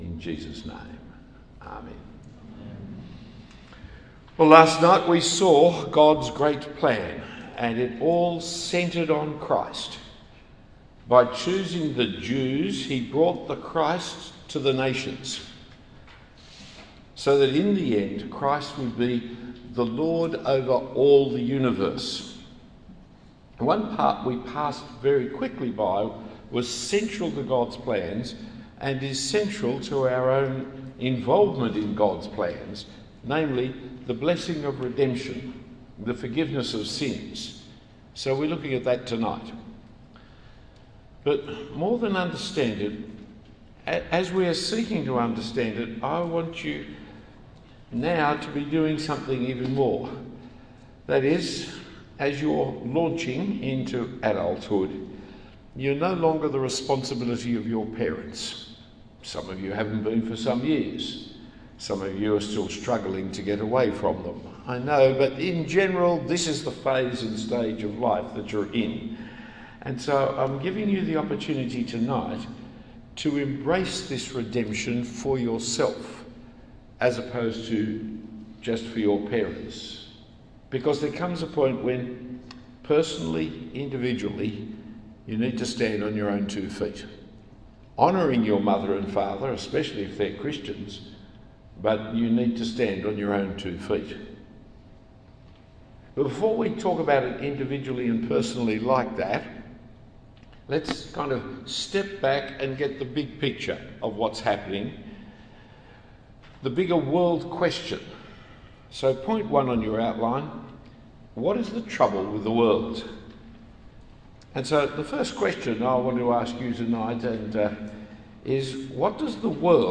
Talk 2 of 6 in 2024 in the series LAUNCH for School Leavers.